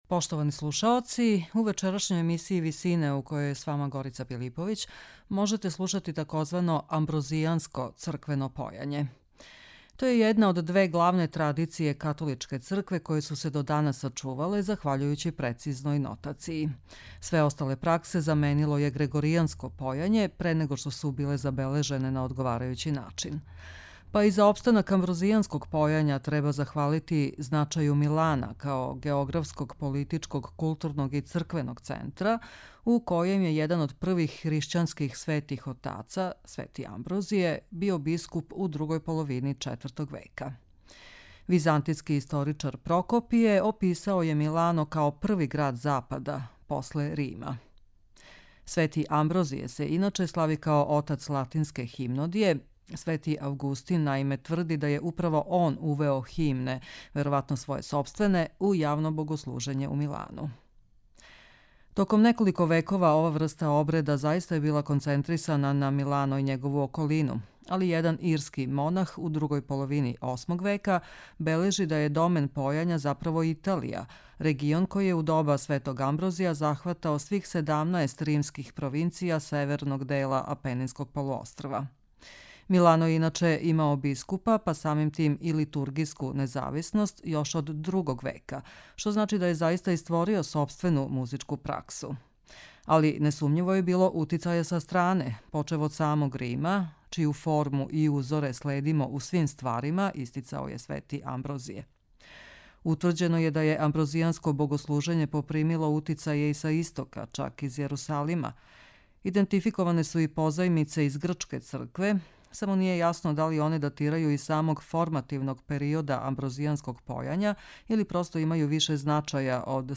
Амброзијанско црквено појање